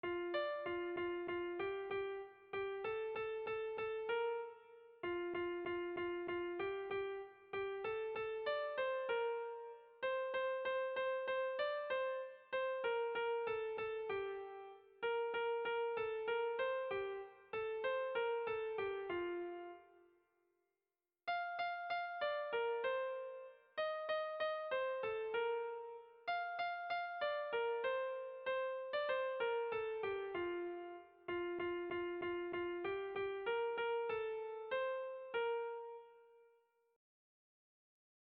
Bertso melodies - View details   To know more about this section
Kontakizunezkoa
Eibar < Debabarrena < Gipuzkoa < Basque Country
Hamalaukoa, txikiaren moldekoa, 9 puntuz (hg) / Bederatzi puntukoa, txikiaren moldekoa (ip)
A1A2BDà